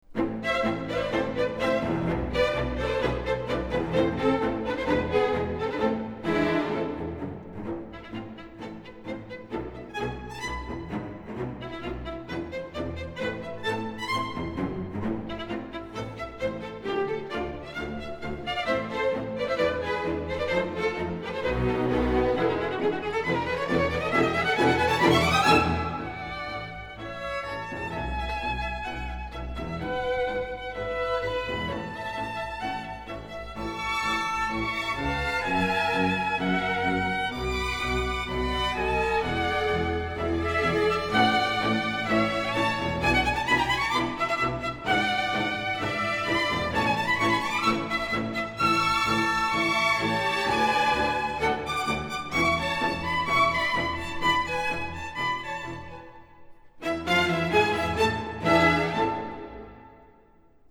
第十变奏曲更是有着浓烈的舞蹈感，因为这是探戈风格变奏曲。低音部分整齐的节奏与高音部分高昂而雄壮的旋律交相辉映，非常出彩。
Tango